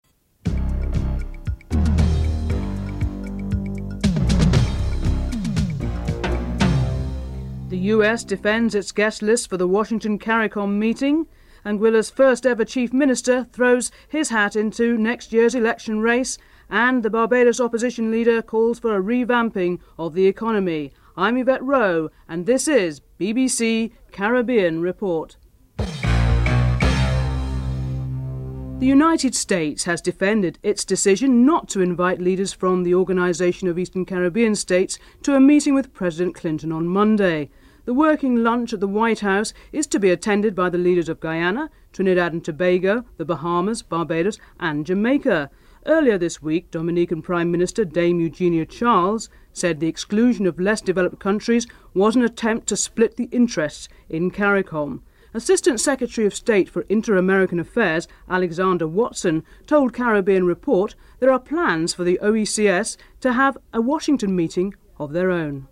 1. Headlines (00:00-00:36)
Interview with Alexander Watson, Assistant Secretary of State for Inter-American Affairs (00:37-02:58)
Interview with Desmond Haynes, West Indian batsman (11:07-14:50)